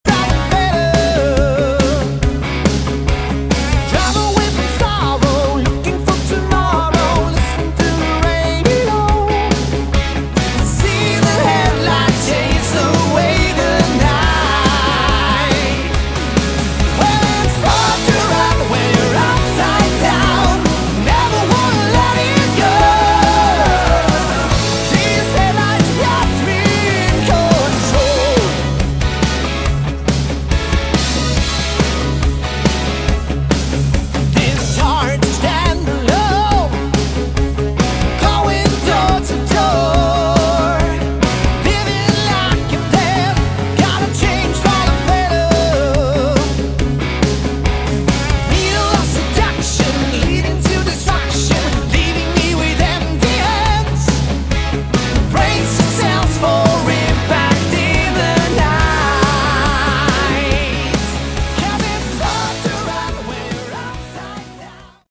Category: Hard Rock
guitar
vocals
bass
drums